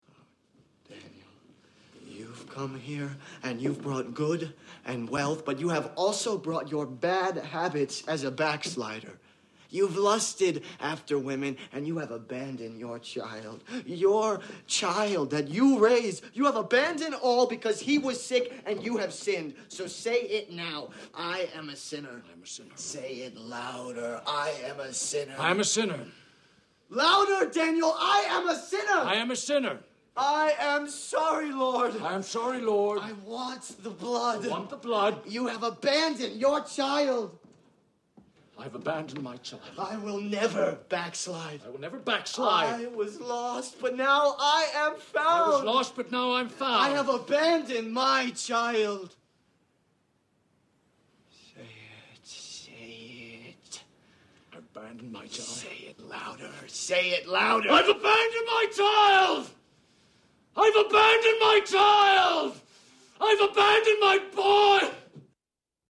Instead, this is a high point of conflict, and you can see Plainview's anger building and building and building.
twbb_baptism.mp3